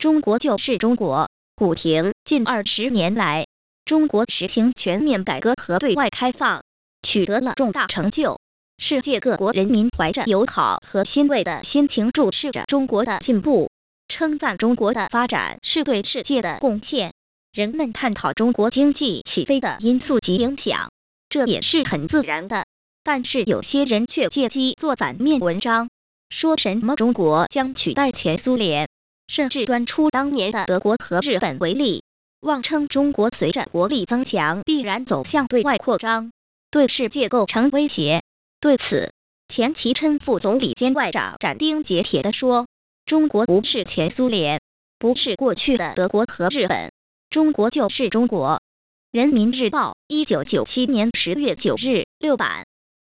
These links point to the three synthetic wave files, of which the first one was generated by our KBCT2.0 Cantonese TTS engine, the next one was generated by our KBCE2.0 Chinese-English Mixed-lingual TTS engine, and the last one was generated by our KD2000 Chinese TTS engine.